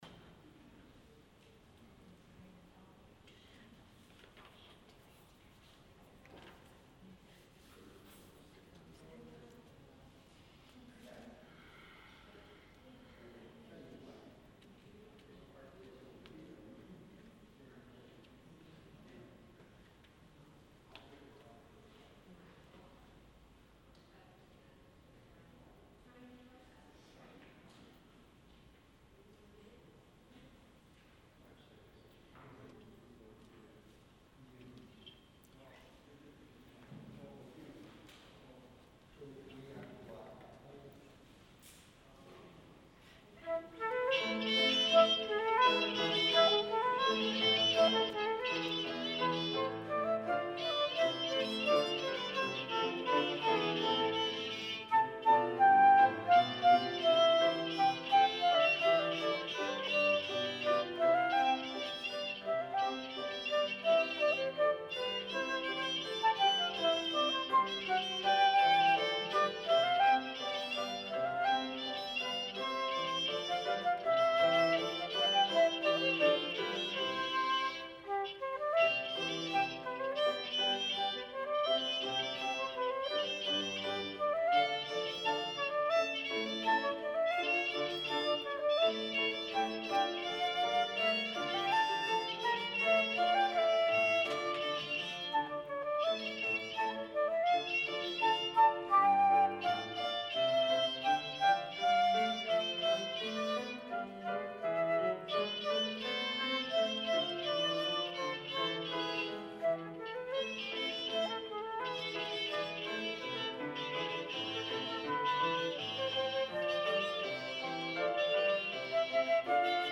Sunday Worship 6-21-20 (Third Sunday after Pentecost)